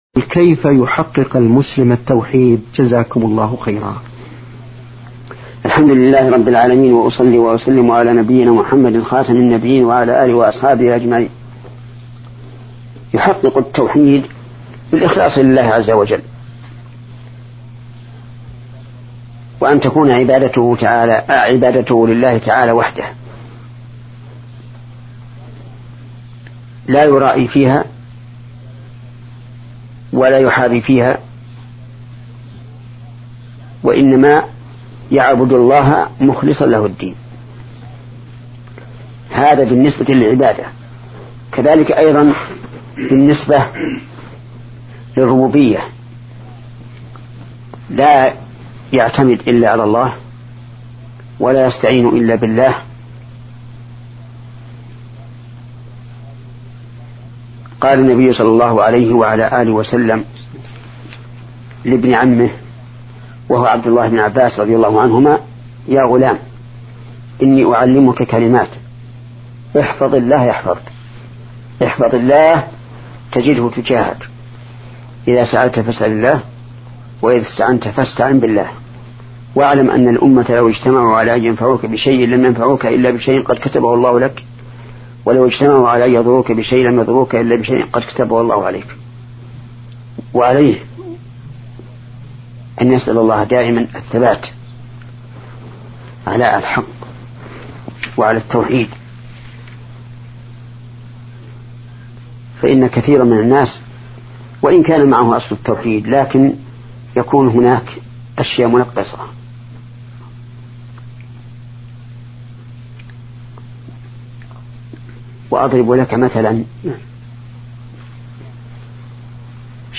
Format: MP3 Mono 16kHz 24Kbps (CBR)